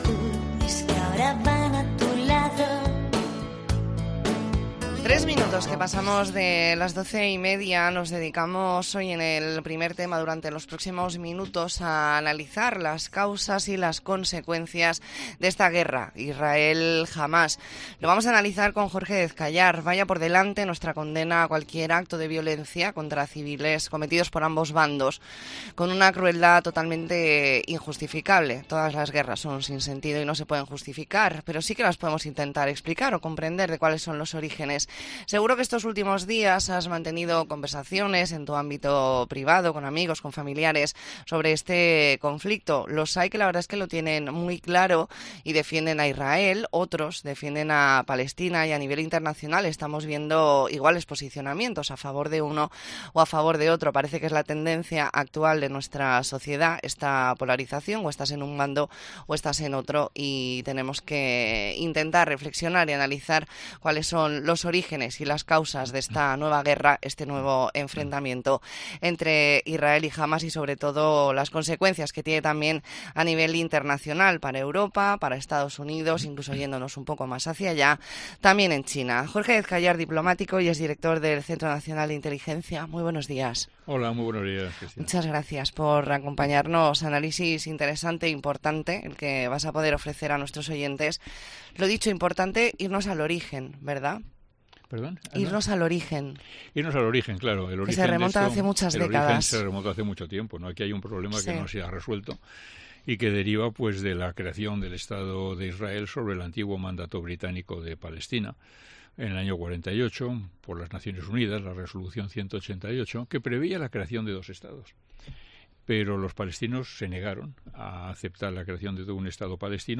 Hablamos con Jorge Dezcallar , diplomático y ex director del Centro Nacional de Inteligencia, sobre la guerra entre Israel y Hamás . Entrevista en La Mañana en COPE Más Mallorca, martes 17 de octubre de 2023.